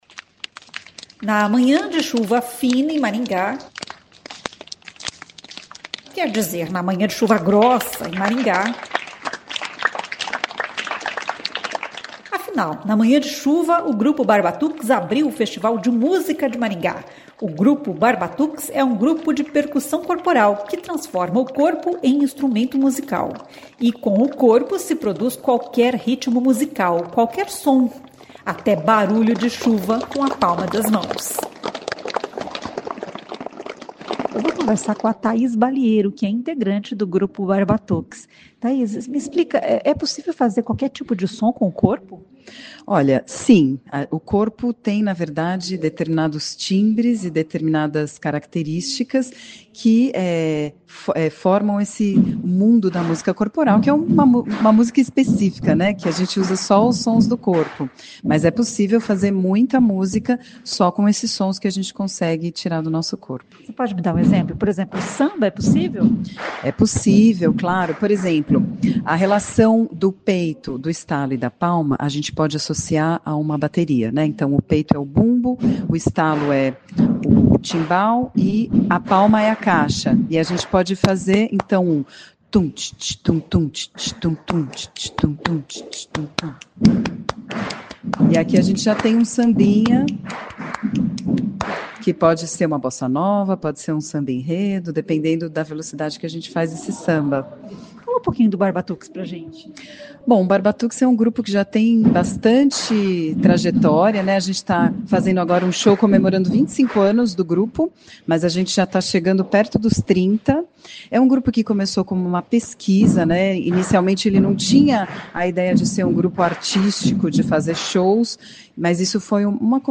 O Grupo Barbatuques, de percussão corporal, abriu o Festival de Música de Maringá nesta segunda-feira (13). Uma oficina com integrantes do grupo reuniu músicos, acadêmicos, artistas e curiosos.
O Grupo Barbatuques é um grupo de percussão corporal que transforma o corpo em instrumento musical.
Até barulho de chuva com a palma das mãos.